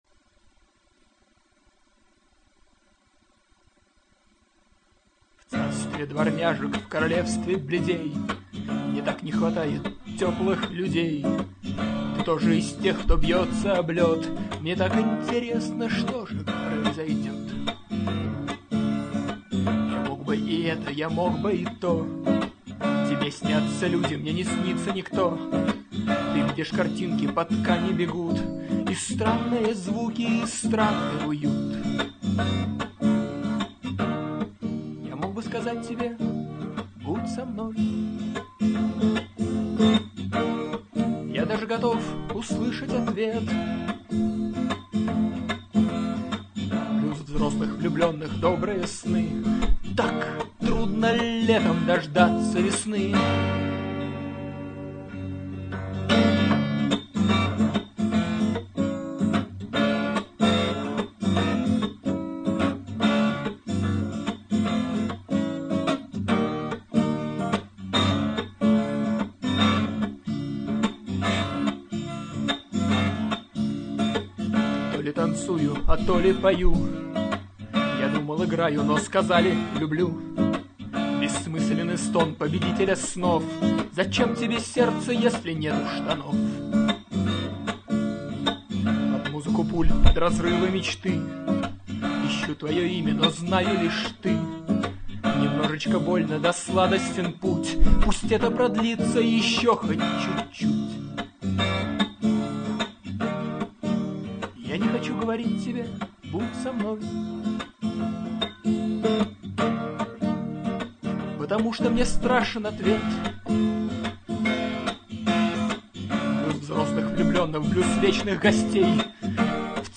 blues.mp3